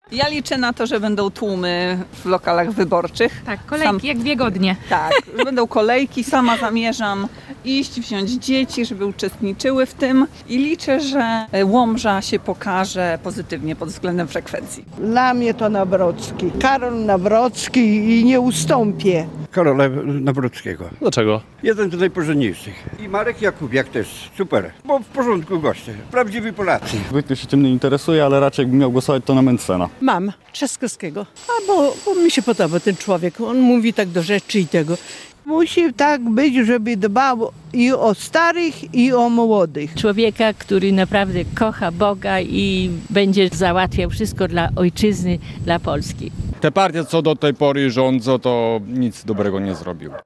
Region: Sonda: Sprawdzamy nastroje mieszkańców przed niedzielnymi wyborami
Zapytaliśmy mieszkańców regionu czy w niedzielę wybierają się do lokali wyborczych, by oddać głos.